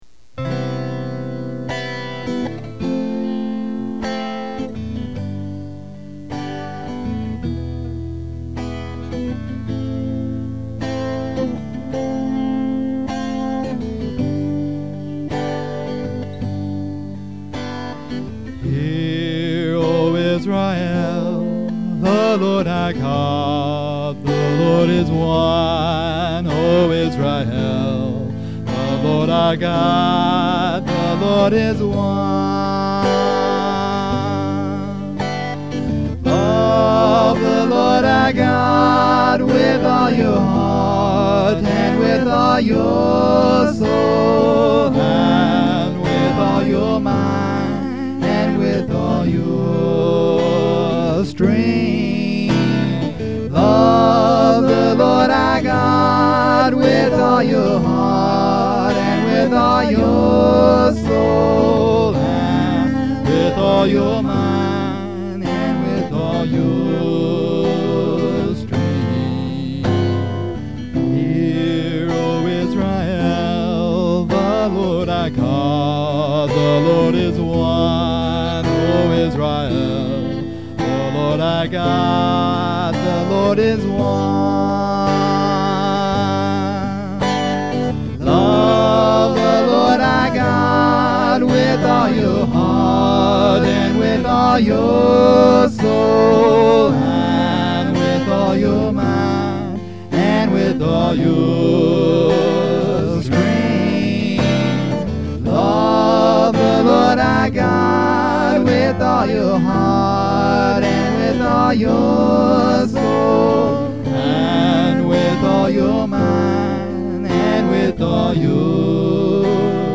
lofi mp3 file